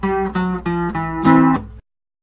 • The Bajo Sexto is a twelve-string guitar.